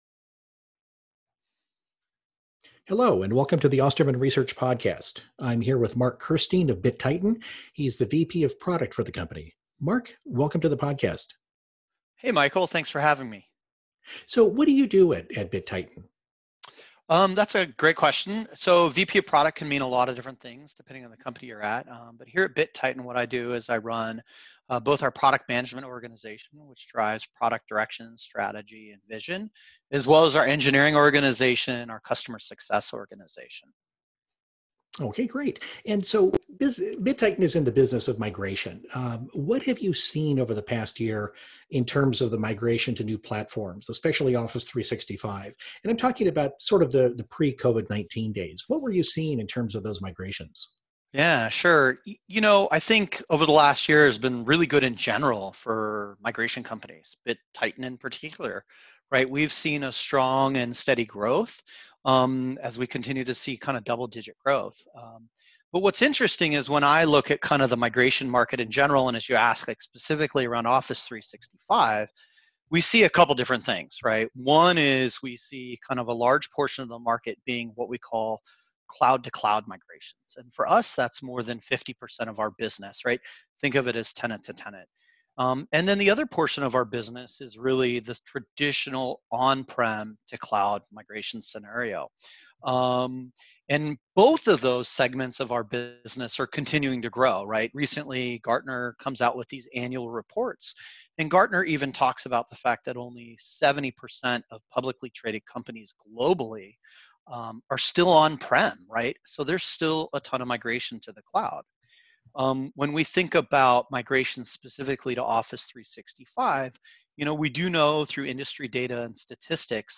A discussion